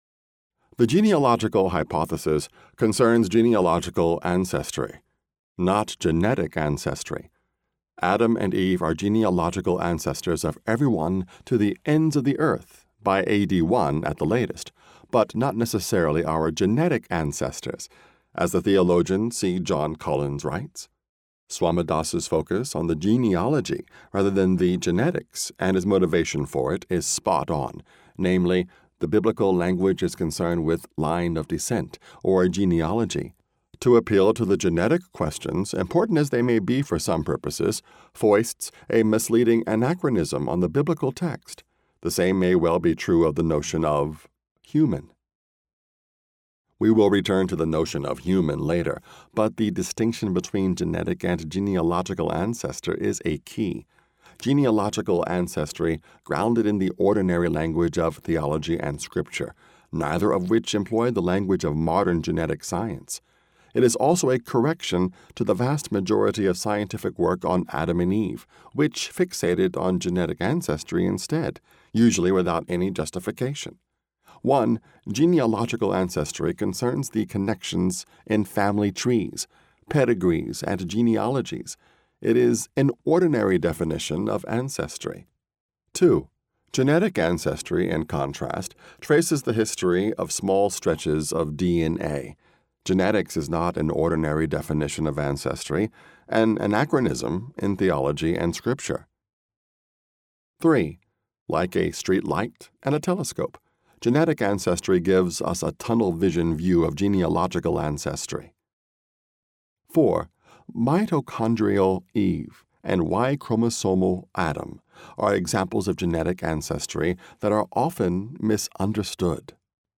I’m really pleased to see this book narrated by an African American, particularly because of how the book engages with questions of race.